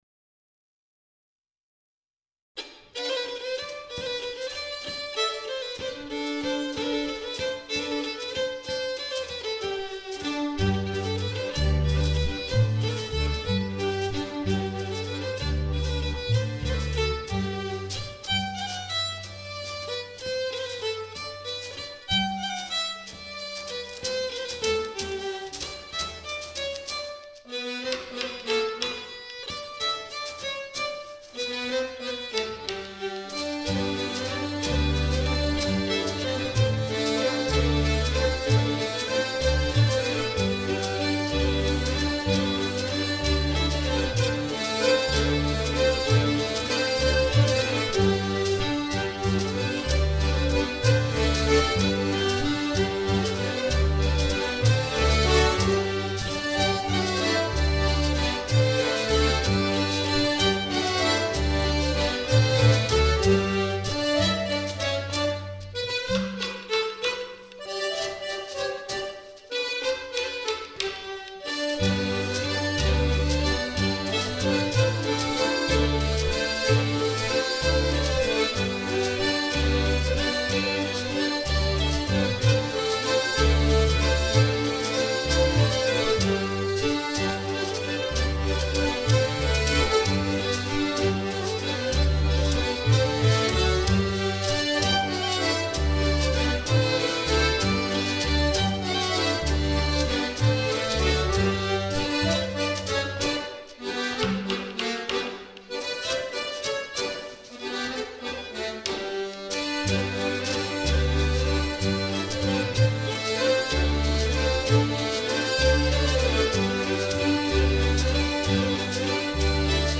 Valse coupée - danse (mp3)